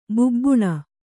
♪ bubbuṇa